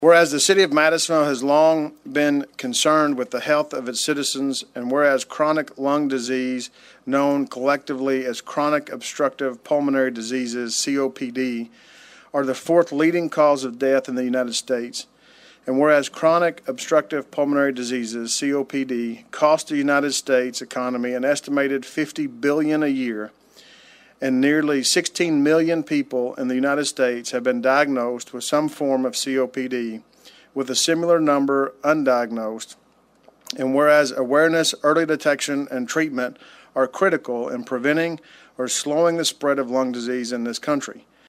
During Monday night’s City Council meeting, Mayor Cotton presented the proclamation.